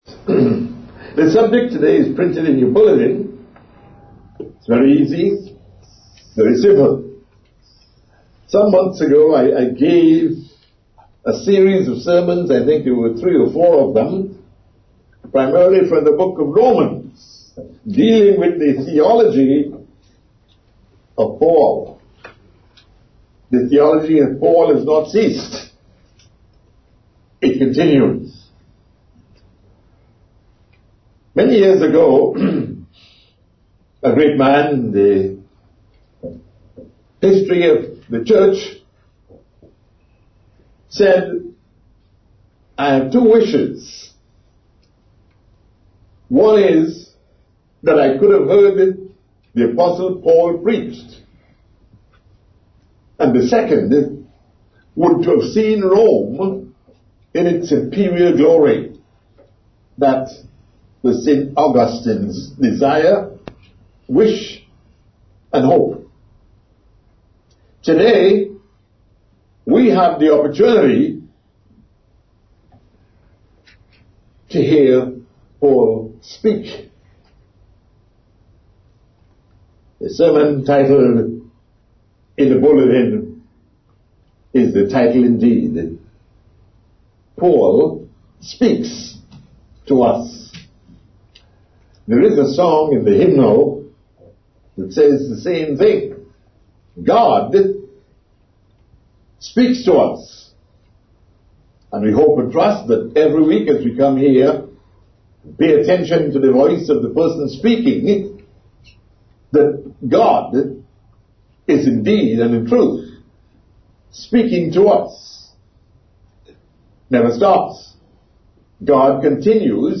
A detailed reading of Paul's letter to to the saints in Philippi, to see what he has to say to us today.
Sermons